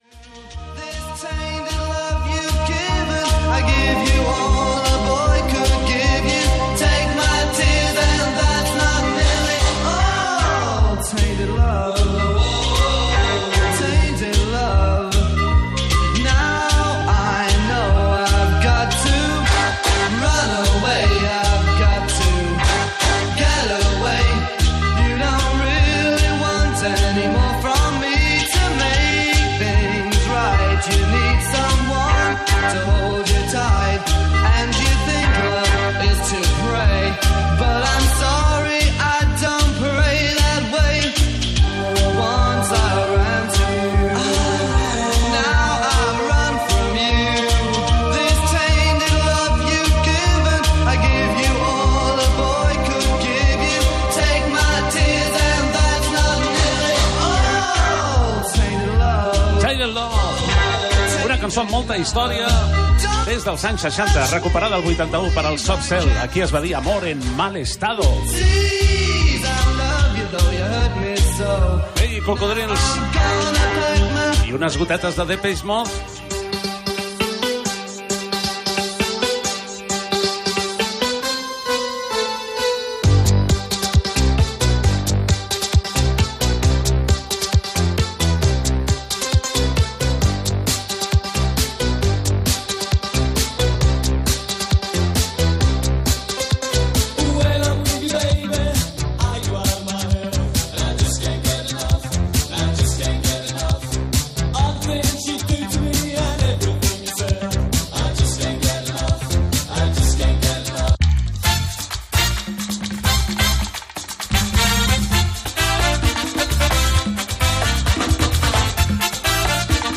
Especial cap d'any 2026 i 31 anys del Cocodril Club, Temes musicals, dotze campanades
Gènere radiofònic Musical